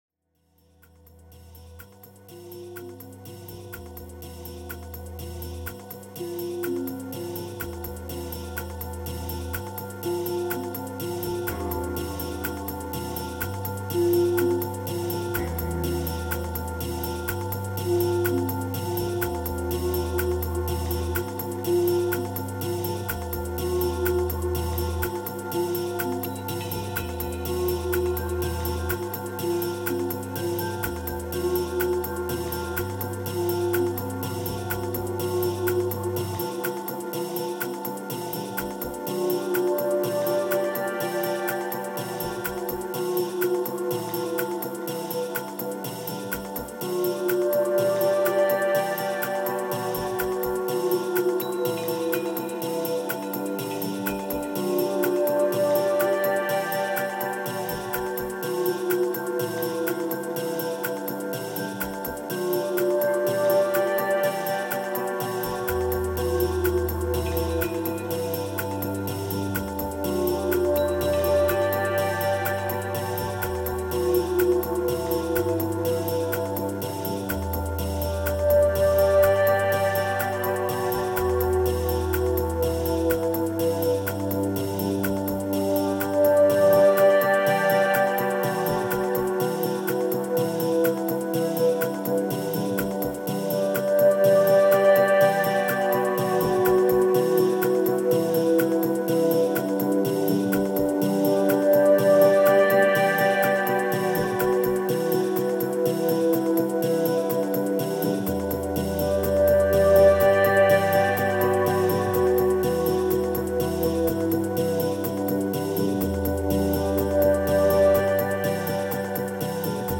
657📈 - 87%🤔 - 62BPM🔊 - 2022-05-18📅 - 844🌟